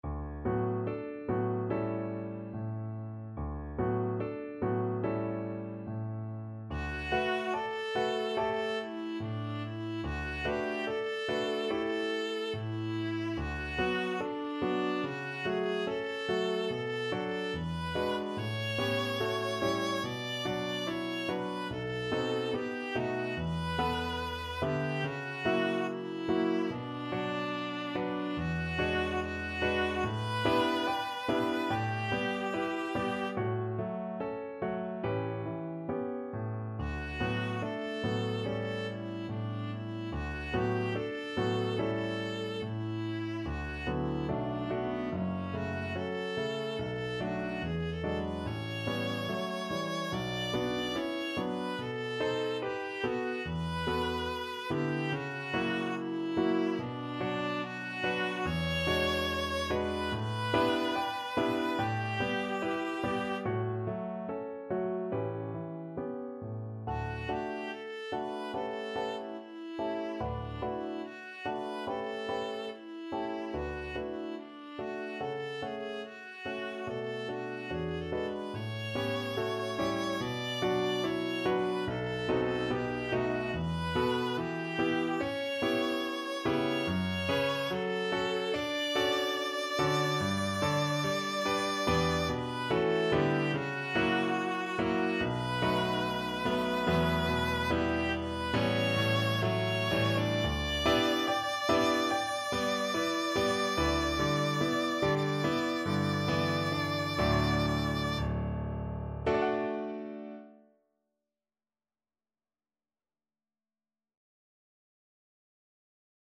4/4 (View more 4/4 Music)
~ = 72 In moderate time
Classical (View more Classical Viola Music)